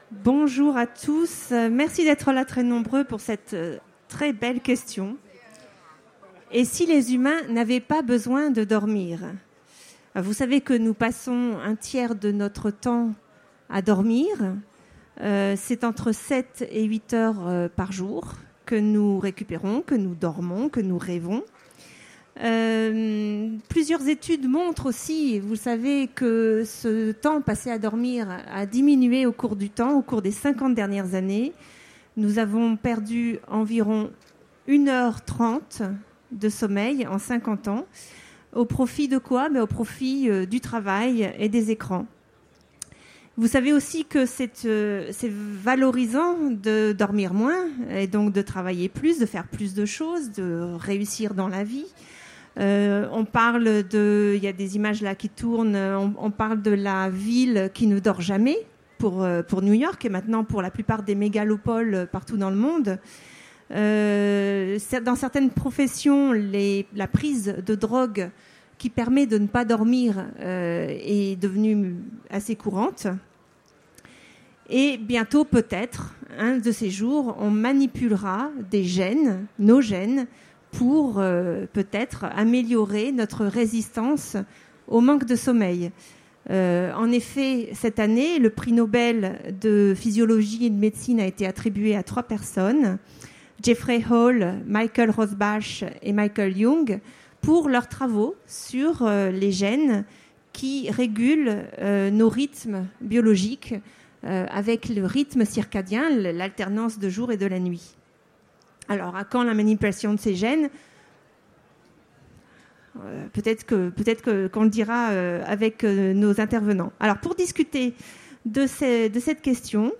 Utopiales 2017 : Conférence Et si les humains n’avaient plus besoin de dormir ?